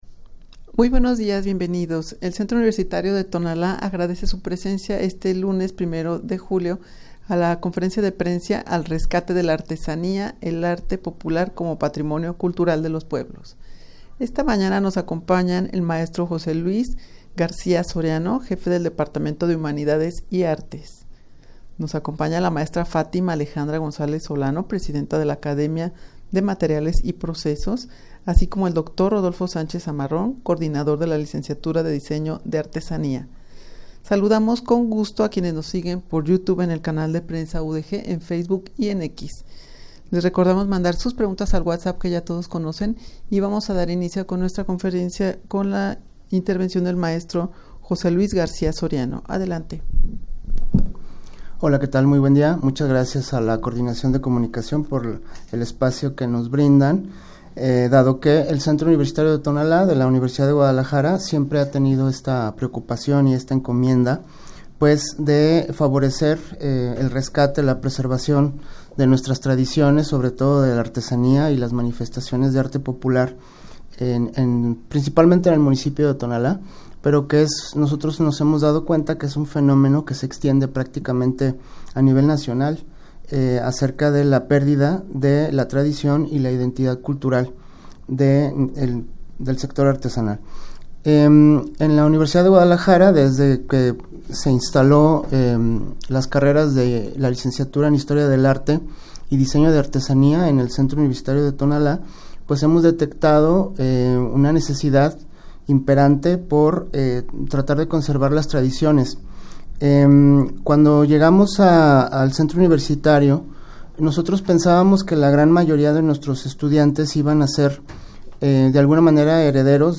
rueda-de-prensa-al-rescate-de-la-artesania-y-el-arte-popular-como-patrimonio-cultural-de-los-pueblos.mp3